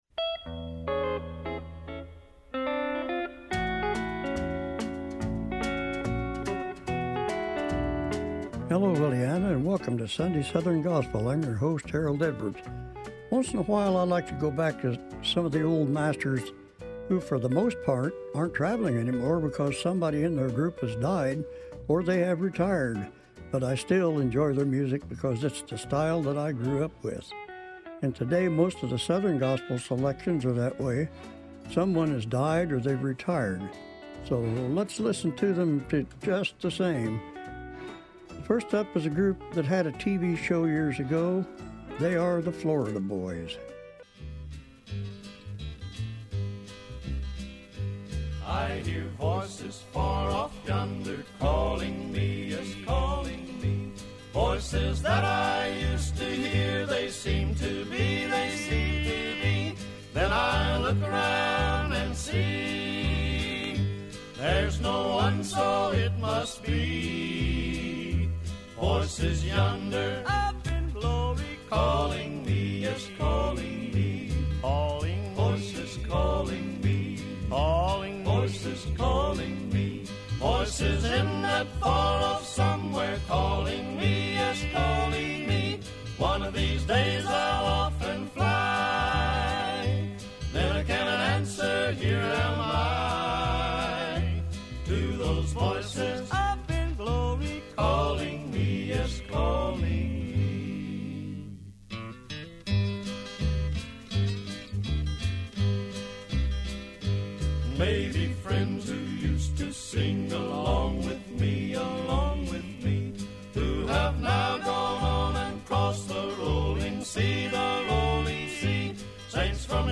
sunday-southern-gospel-8-17-25.mp3